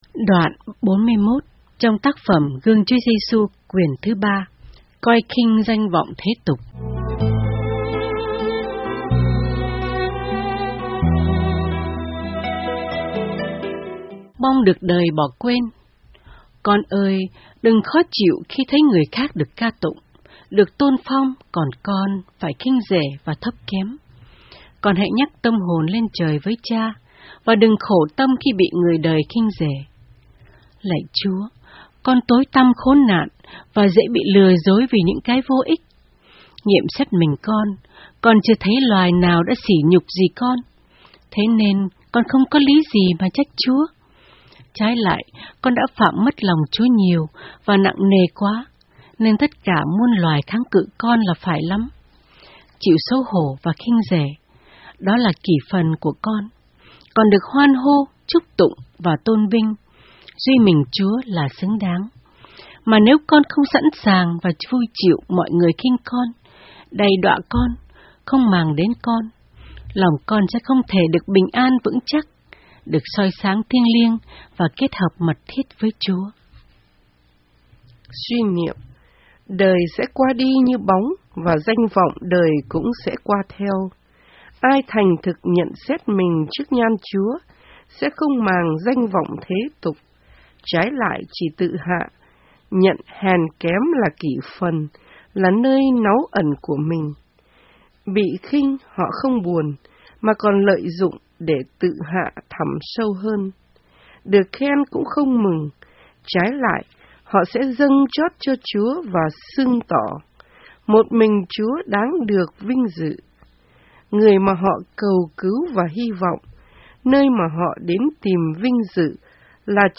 Audio Book Guong Chua Giesu Cuon 3 :: Memaria